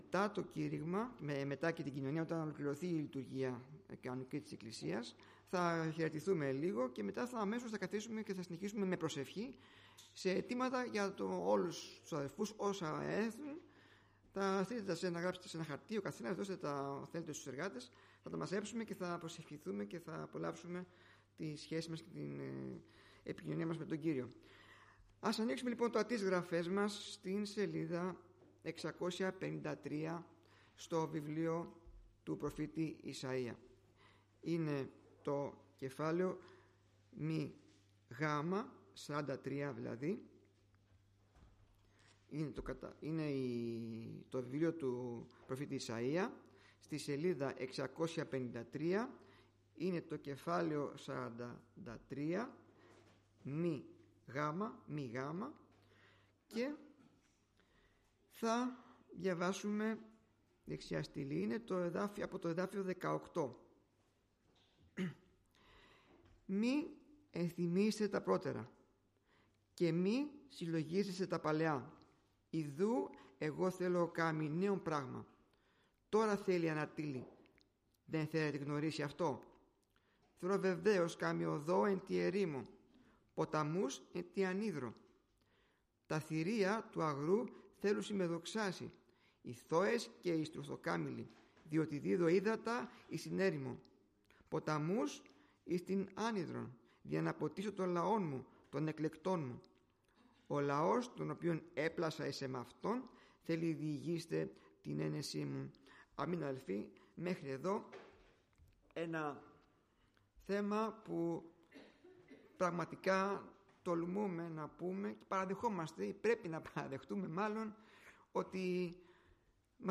Κυριακάτικο Κήρυγμα